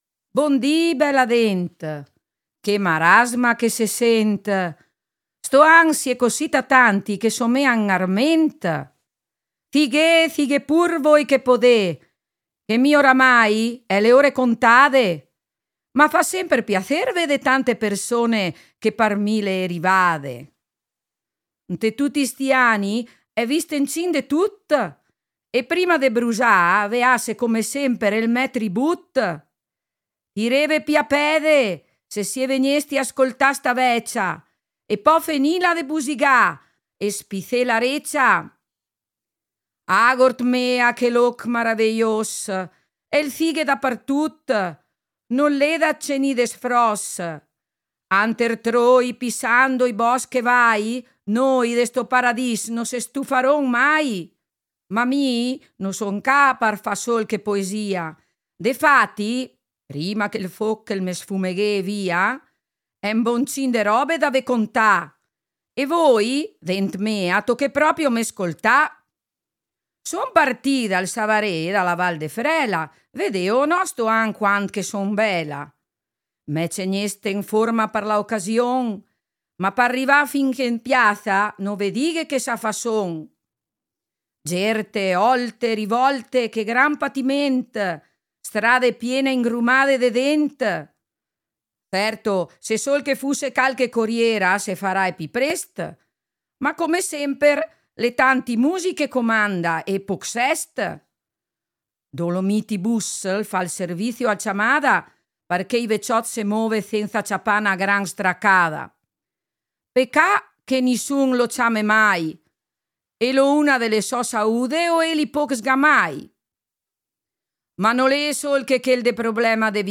AGORDO Tutto pronto sul Broi per ascoltare il testamento della Vecia Popa.